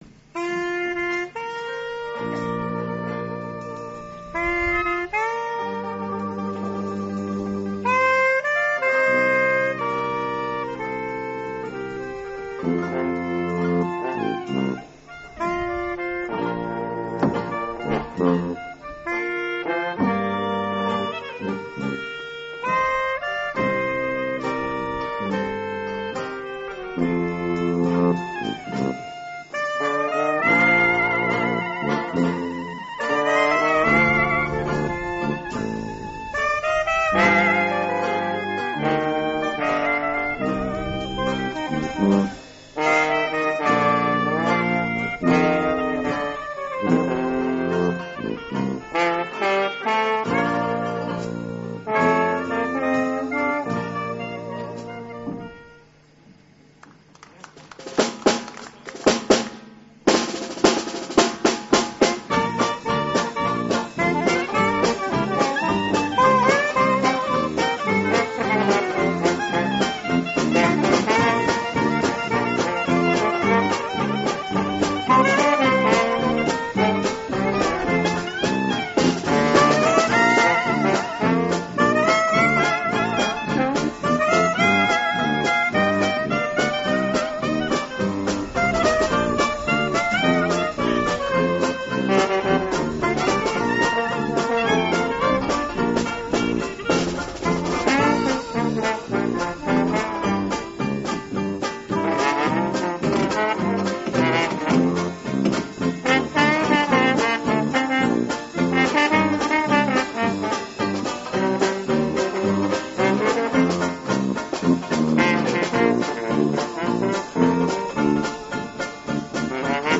Bon désolé pour le mp3 foireux c'est tout ce que j'ai trouvé, ça dévie un peu de l'hymne traditionnel provençal mais ça donne une idée de l'air...